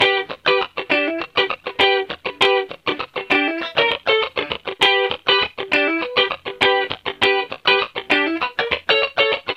Loops guitares rythmique- 100bpm 2
Guitare rythmique 30